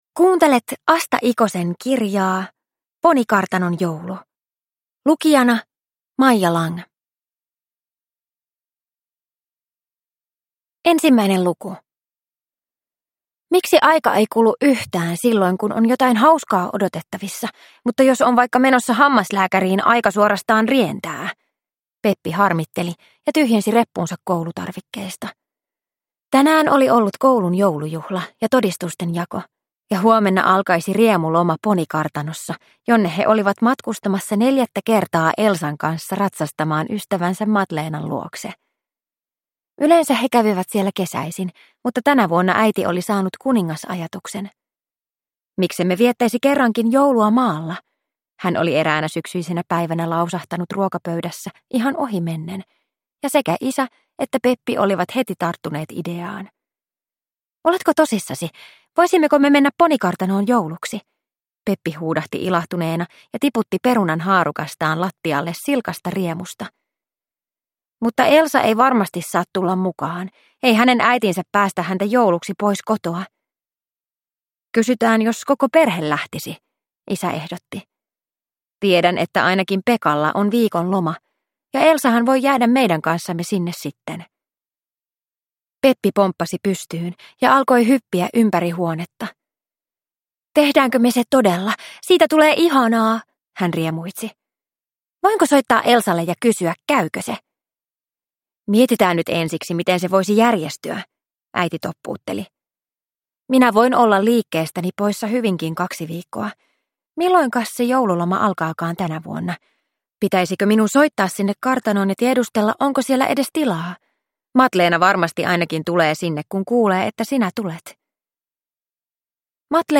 Ponikartanon joulu – Ljudbok